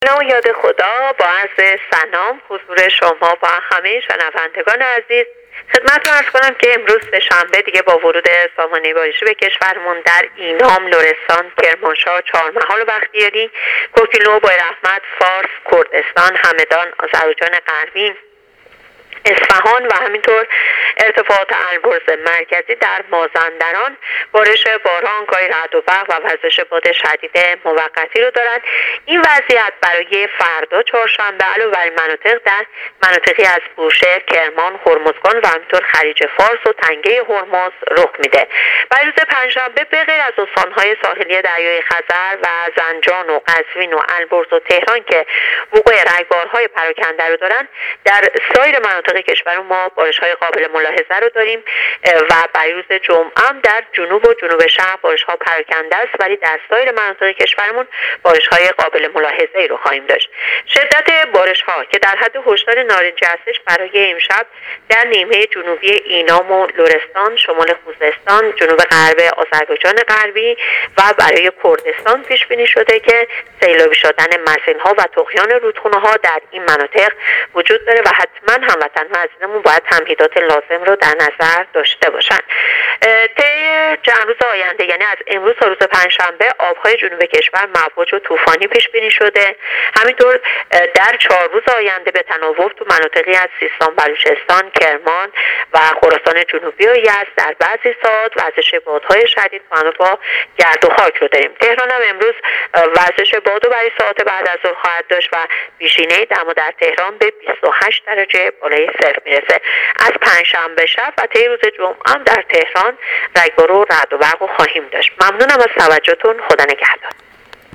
گزارش رادیو اینترنتی پایگاه‌ خبری از آخرین وضعیت آب‌وهوای ۱۱ اردیبهشت؛